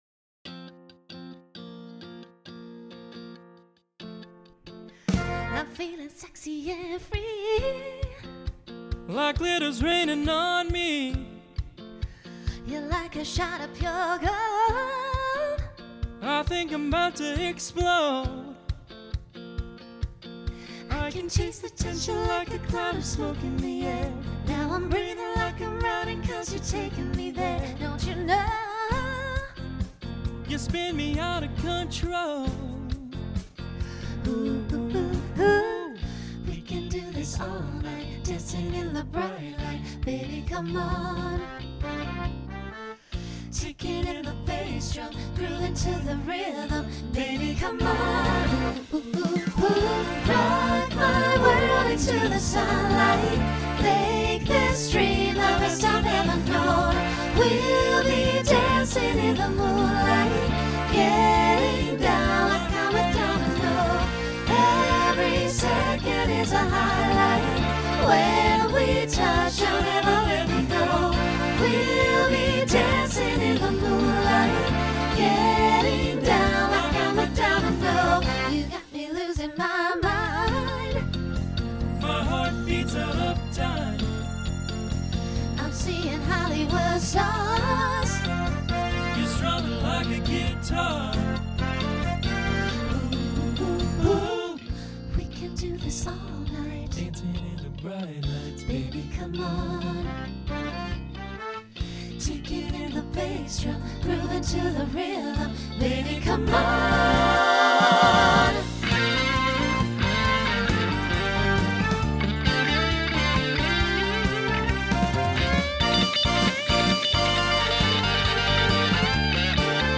Pop/Dance Instrumental combo
Transition Voicing SATB